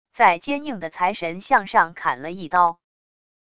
在坚硬的财神像上砍了一刀wav音频